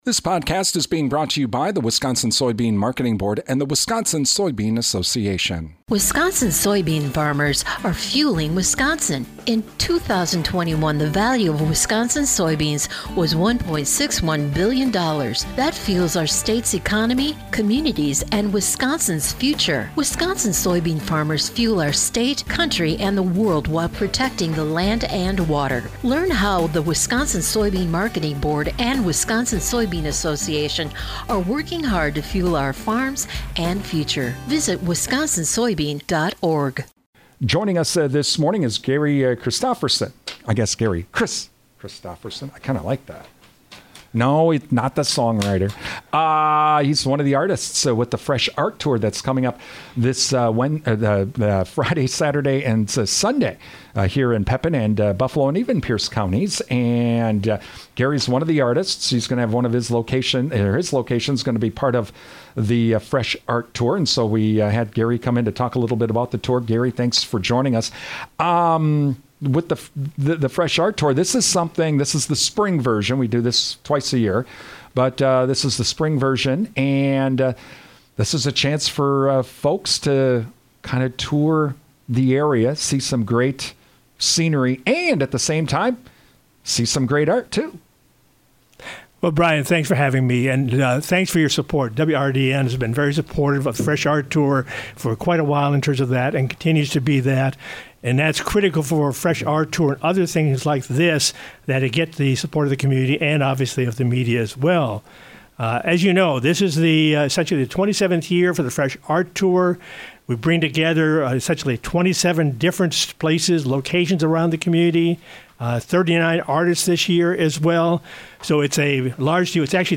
Fresh Art Tour interview with WRDN.